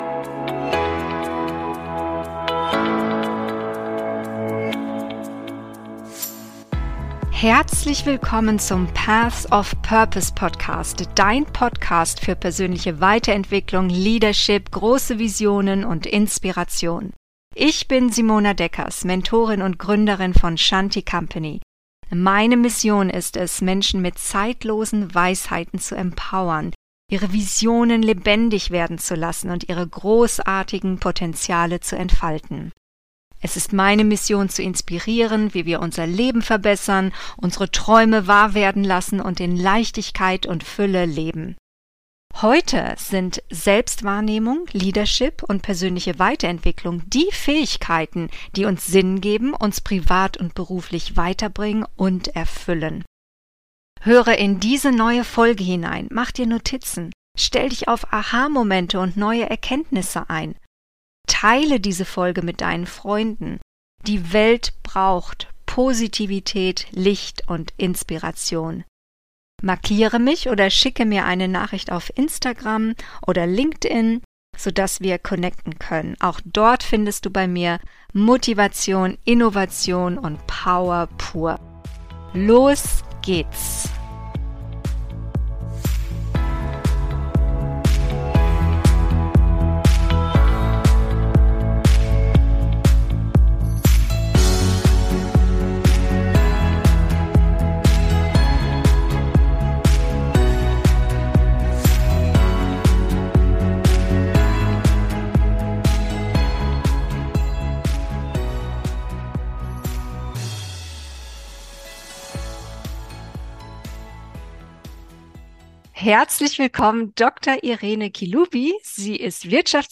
Die Zukunft ist jung und alt - Interview